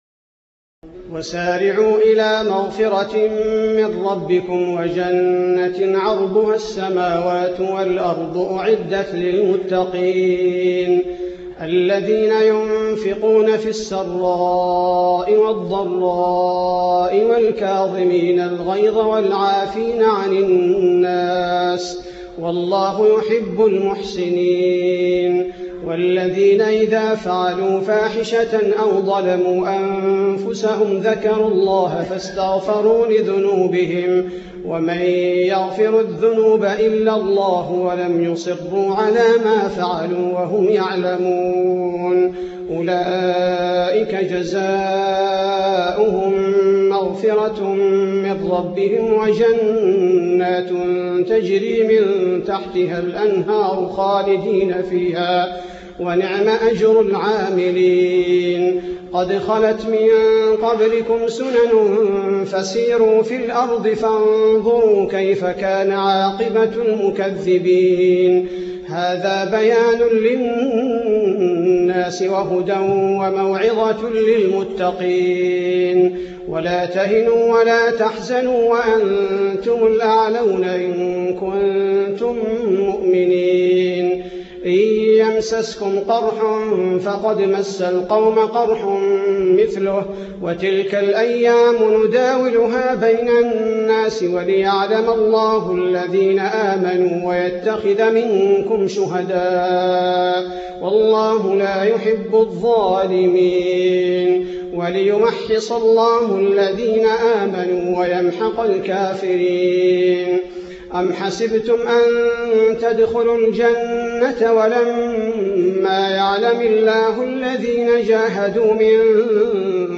تهجد ليلة 23 رمضان 1428هـ من سورة آل عمران (133-200) Tahajjud 23 st night Ramadan 1428H from Surah Aal-i-Imraan > تراويح الحرم النبوي عام 1428 🕌 > التراويح - تلاوات الحرمين